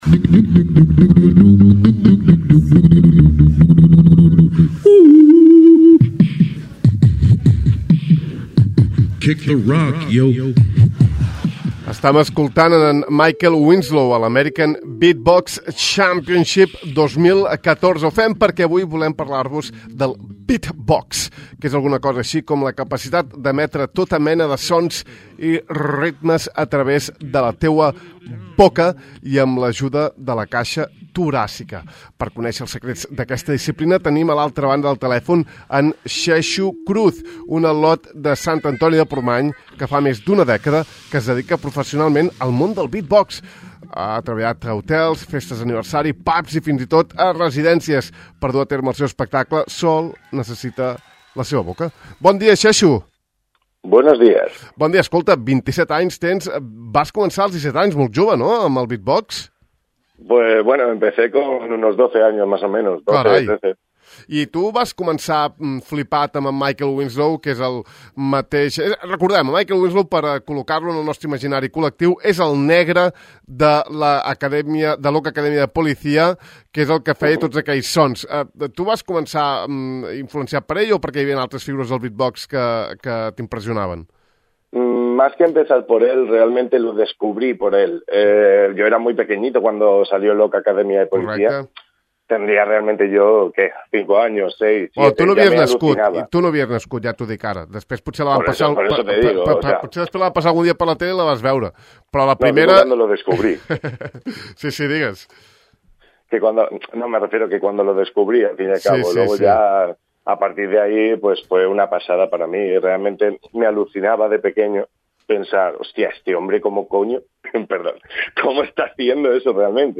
El beat box és la capacitat d'emetre tota mena de sons i ritmes a través de la boca i amb l'ajuda de la caixa toràcica.